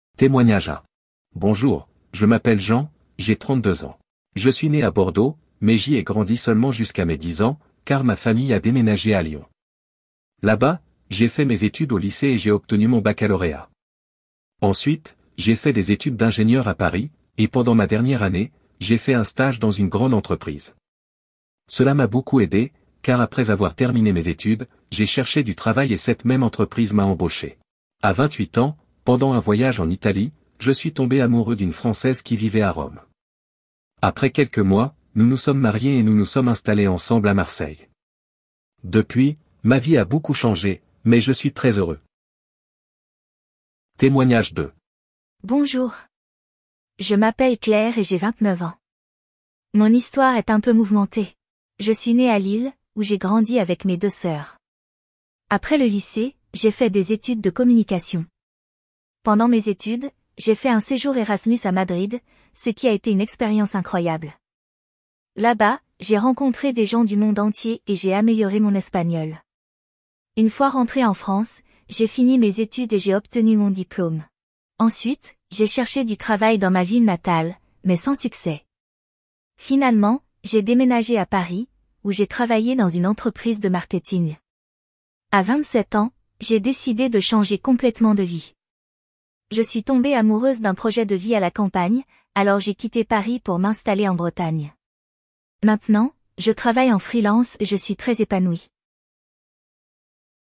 Vous trouverez une compréhension orale avec deux témoignages, des activités lexicales pour enrichir le vocabulaire des apprenants, des exercices sur le passé composé, parfaits pour consolider les bases grammaticales et une petite production orale, permettant à chacun de raconter son propre parcours de vie.